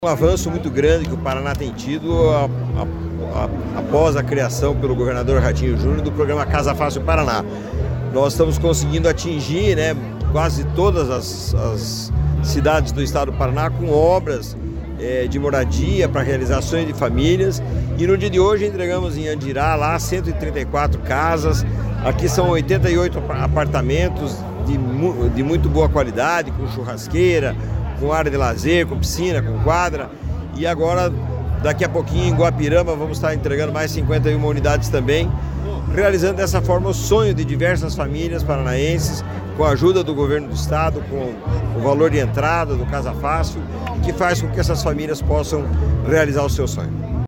Sonora do presidente da Cohapar, Jorge Lange, sobre a entrega de apartamentos em Santo Antônio da Platina